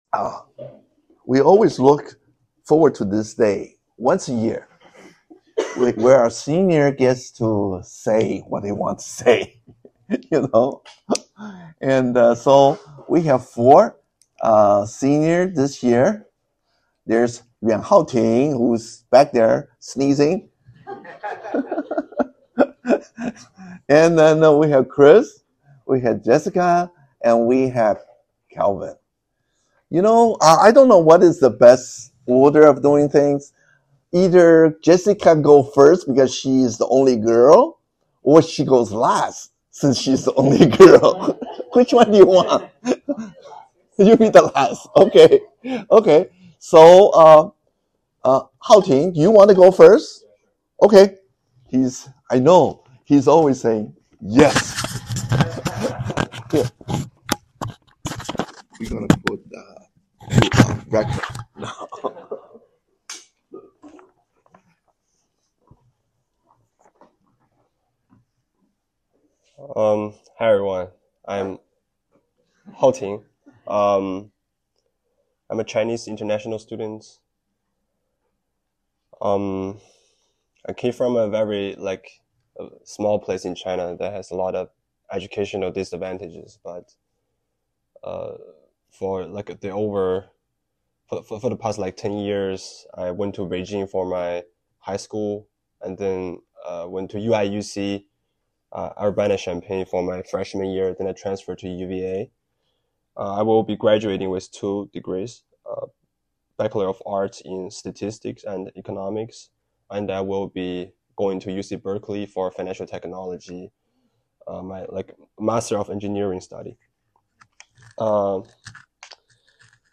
Testimonies
College Seniors